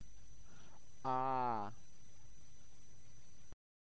À_à - letter, like English a in such words - bath, after, but Ukrainian is rather short.